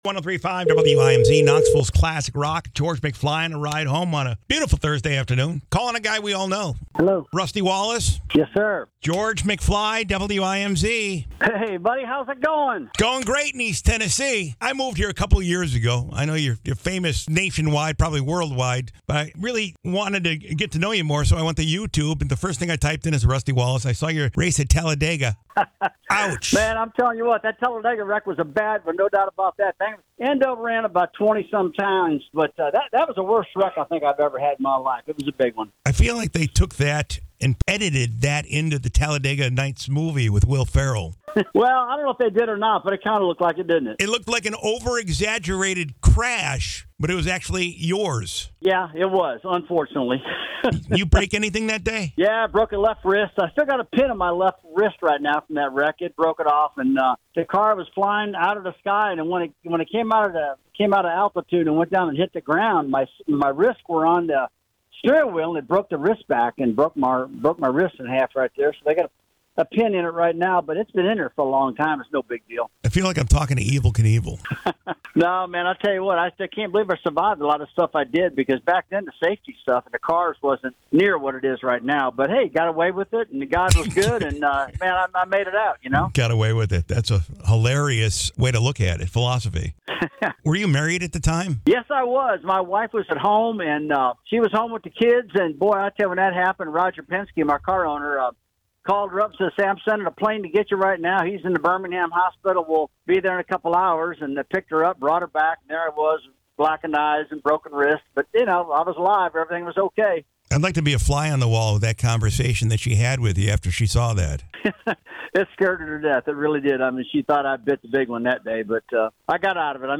rusty-wallace-interview.mp3